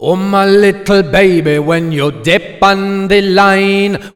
OLDRAGGA1 -L.wav